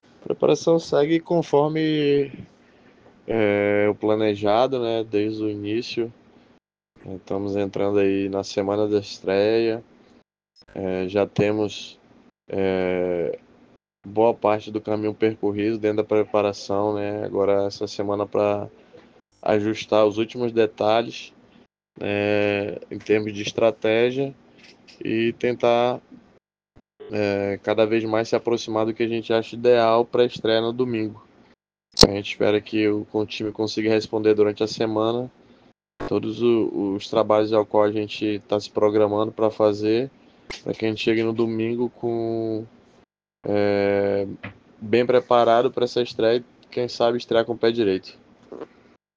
Ouça a sonora do professor aqui: